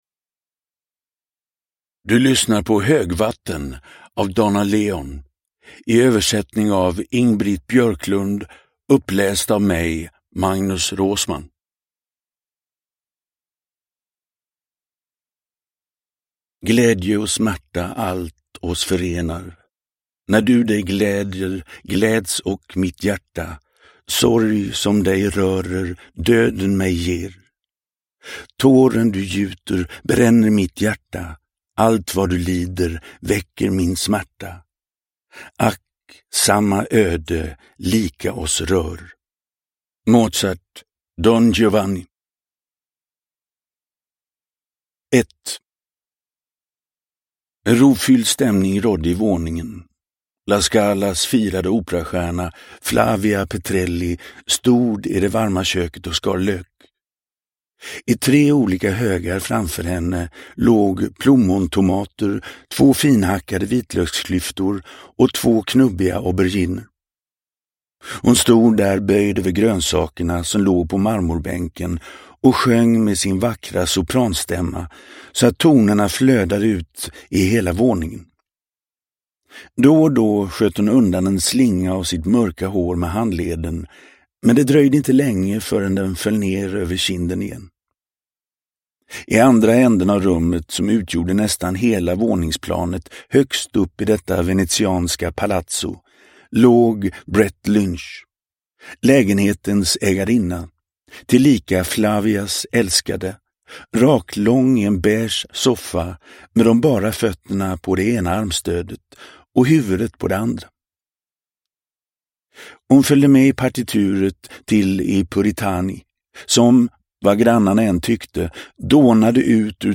Högvatten – Ljudbok – Laddas ner
Uppläsare: Magnus Roosmann